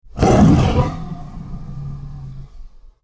Sound Effect: Tiger Roar - The AI Voice Generator
Listen to the AI generated sound effect for the prompt: "Tiger Roar".